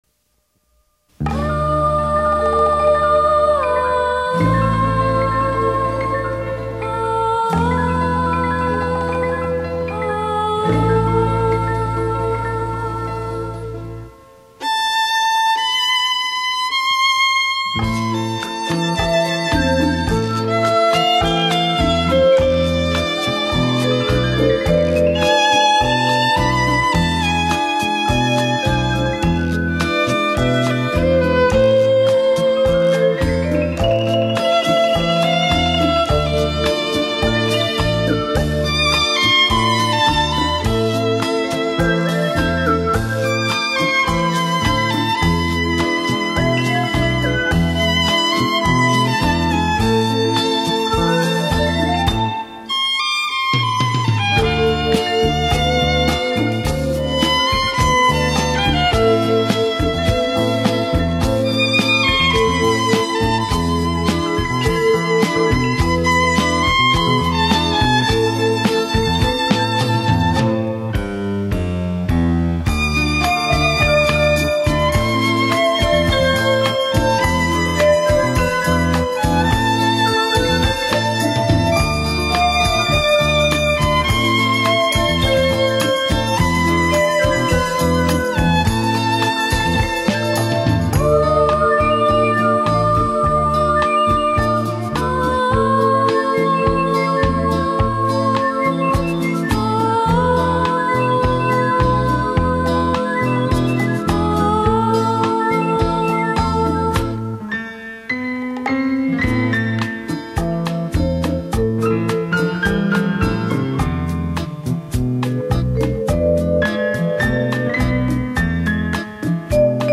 [12/24/2007]^祝各位朋友们圣诞快乐^ 小提琴曲《只见雪花不见你》 激动社区，陪你一起慢慢变老！